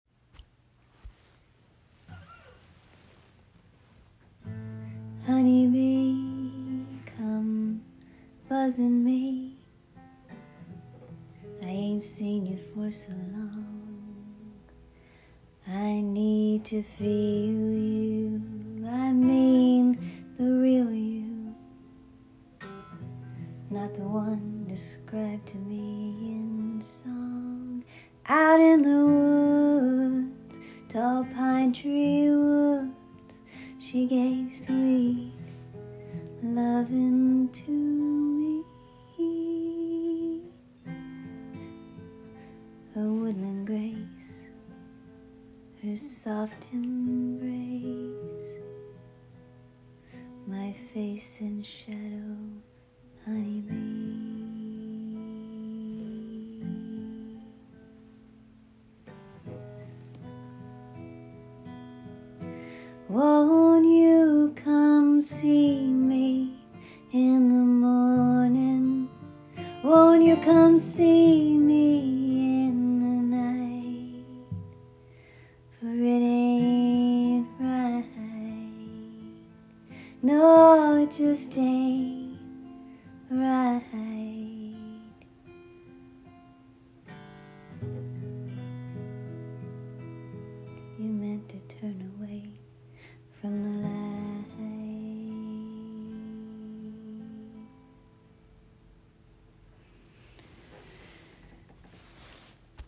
A collection of little songs I record here and there. These are all done via my iPhone voice memo function and are imperfect and in the moment